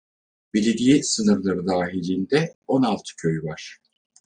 Pronounced as (IPA) /vɑɾ/